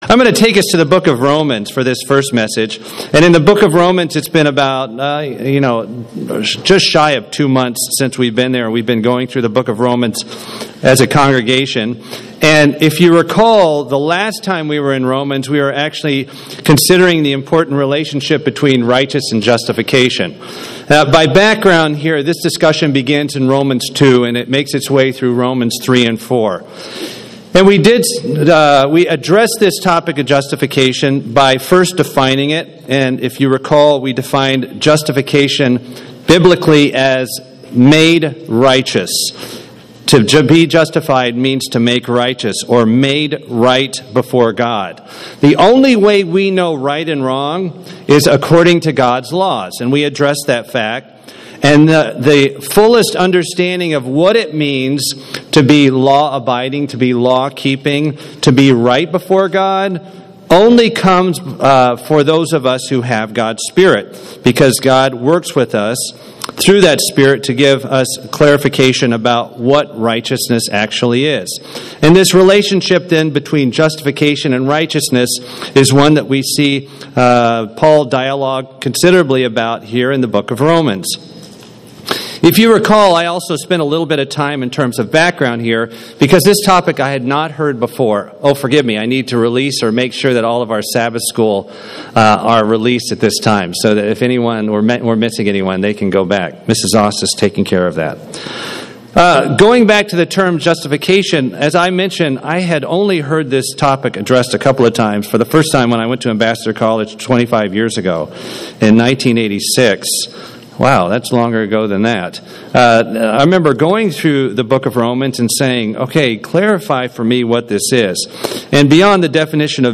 UCG Sermon Romans justification Transcript This transcript was generated by AI and may contain errors.